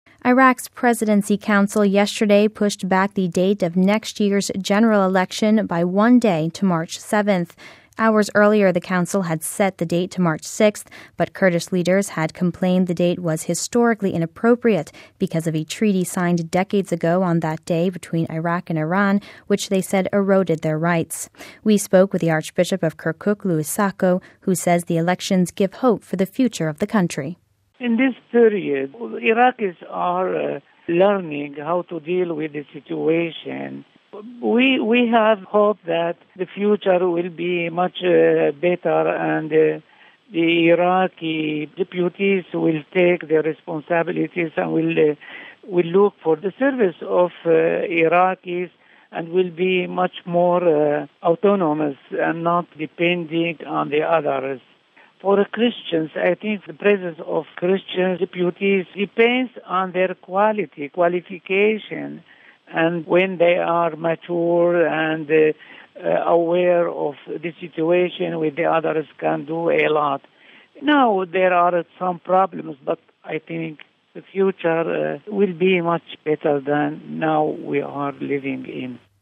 We spoke with the Archbishop of Kirkuk, Louis Sako, who says the elections give hope to the future...